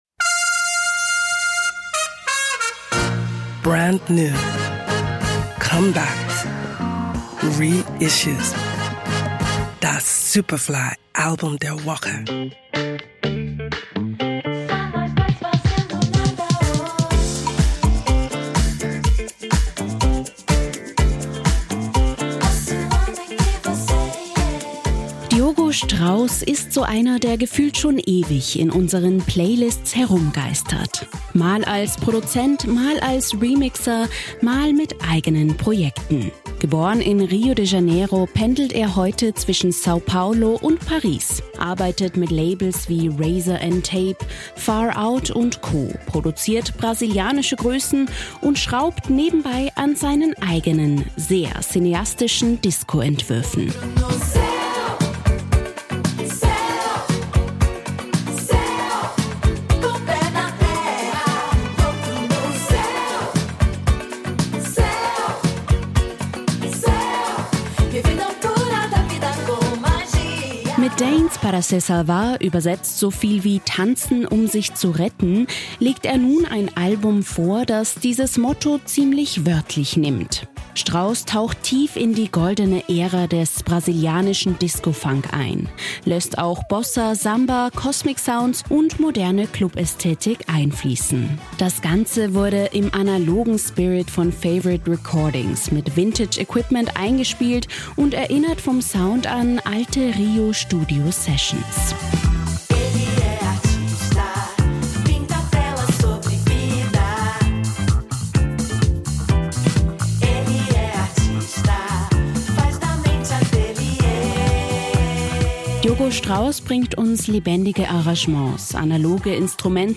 sehr cineastischen Disco-Entwürfen